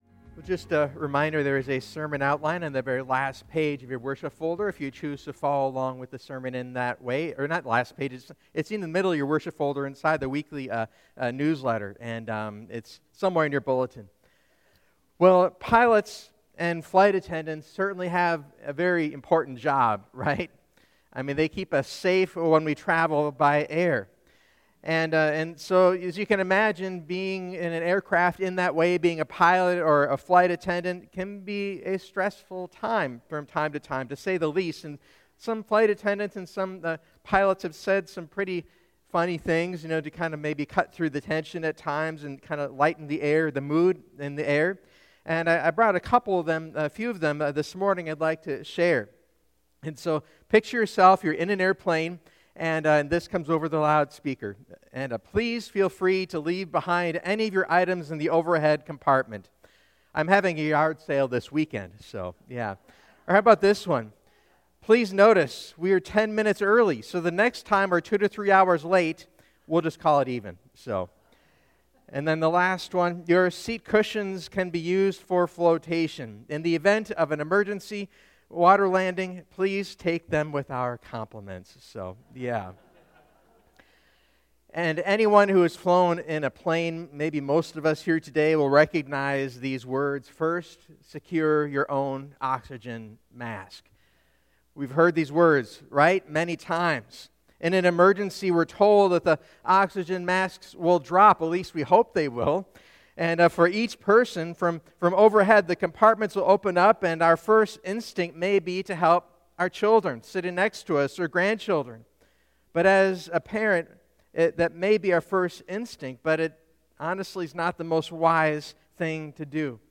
Sermon-2026-03-15.mp3